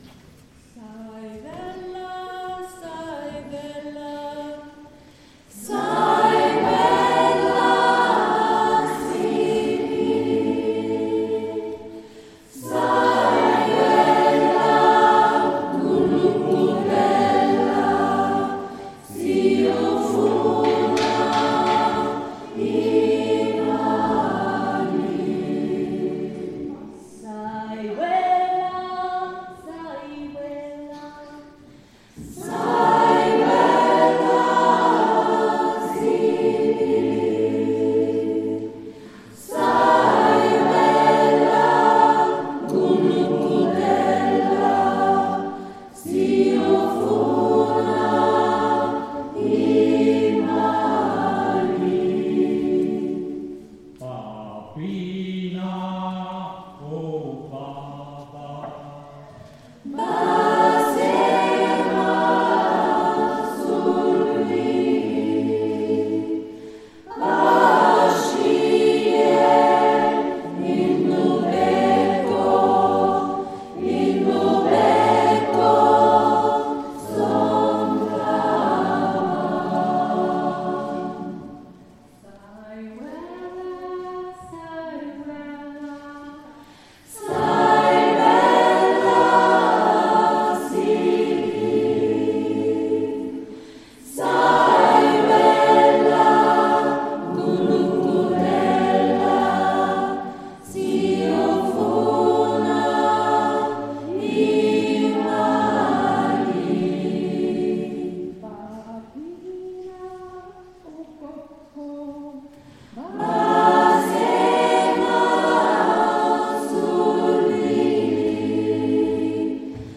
Die afrikanischen Lieder aus dem Gottesdienst